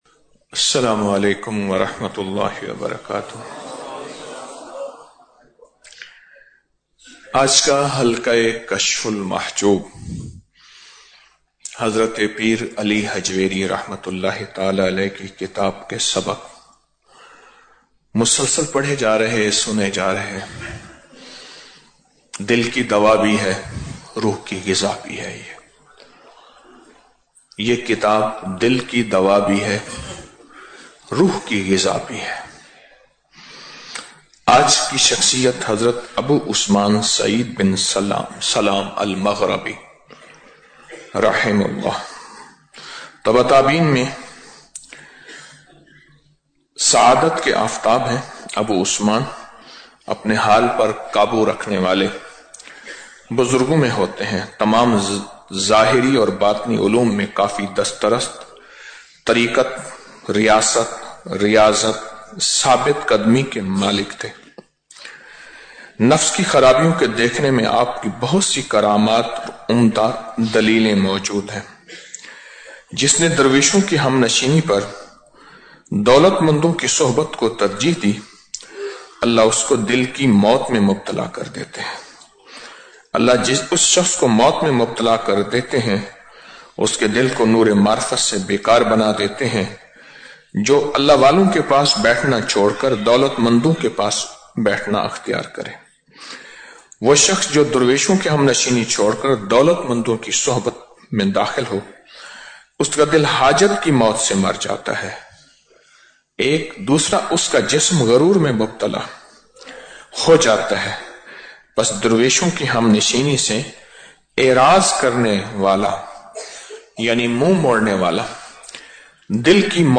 Audio Speech - 04 Ramadan After Salat Ul Fajar - 05 March 2025